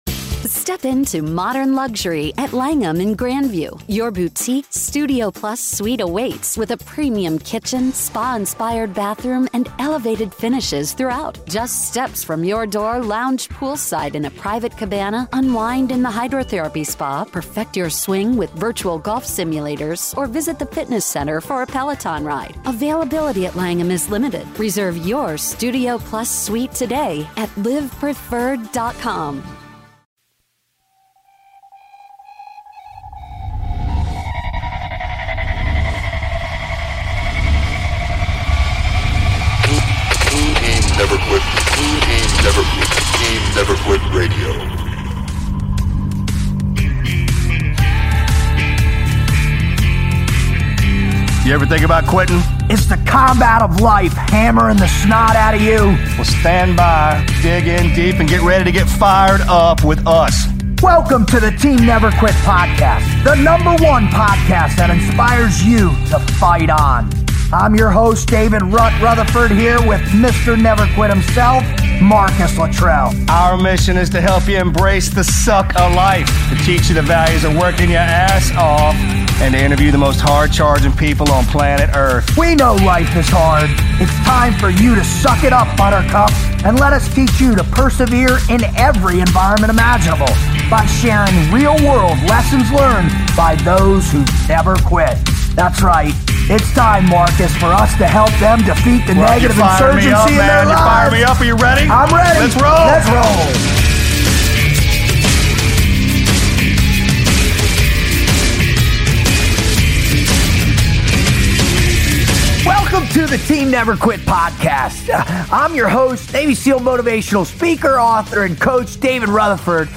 Get ready for the most exciting new show available for download, as the Team Never Quit Podcast welcomes Country Music Hall of Fame inductee, southern rock icon, and American patriot, Charlie Daniels.